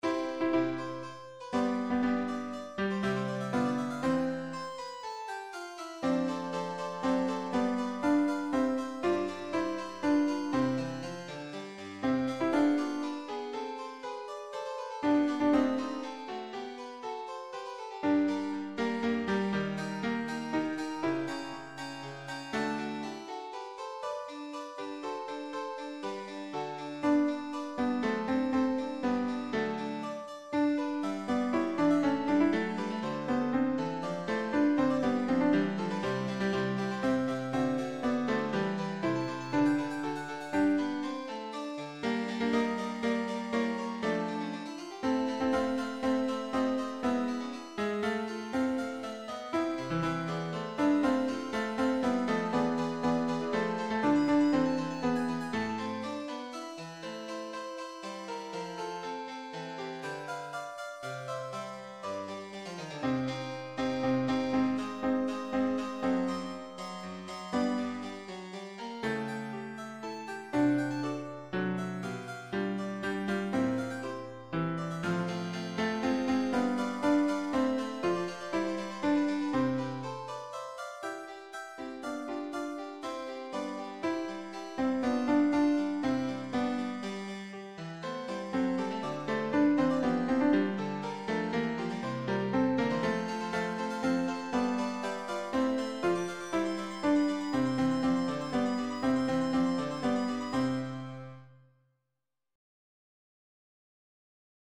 Sans paroles
Voix + chœur en sourdine